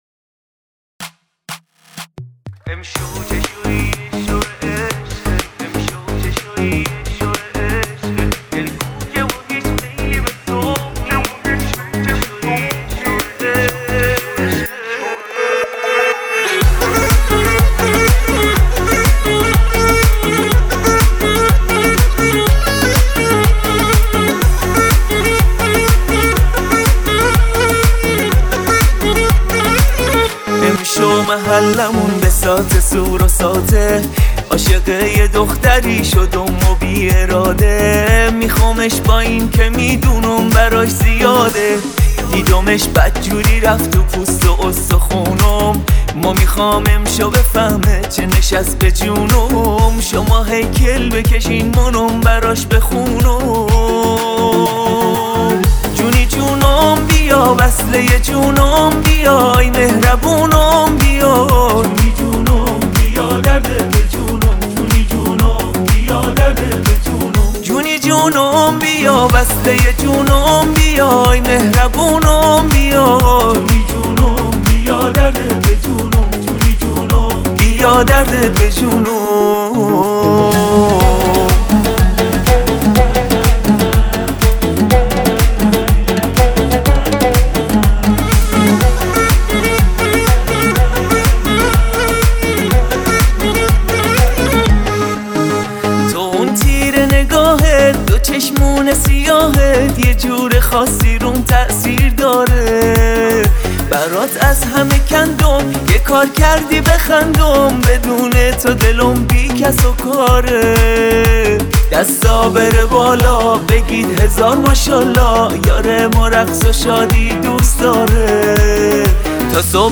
Download New Remix BY